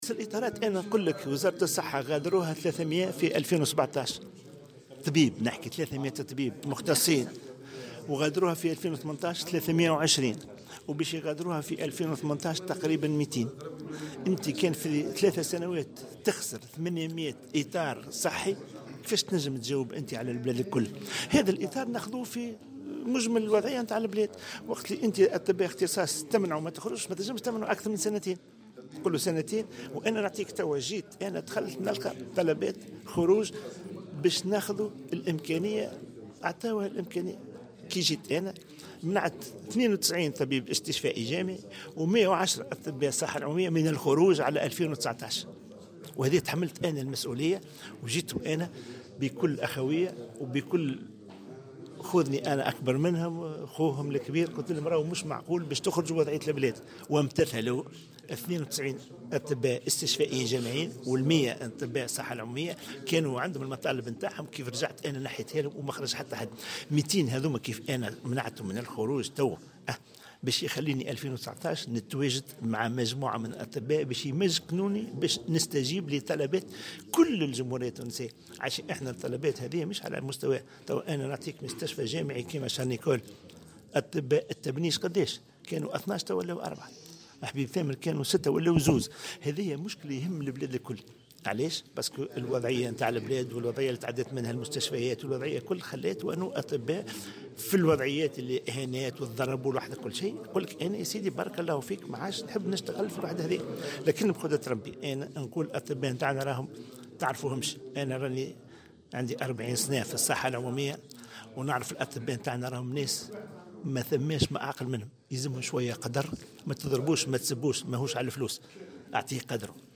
وأضاف في تصريح لمراسل "الجوهرة أف أم" اليوم على هامش زيارته اليوم لولاية القيروان أن حوالي 300 طبيب اختصاص غادروا وزارة الصحة في 2017 مقابل 320 طبيب اختصاص خلال سنة 2018.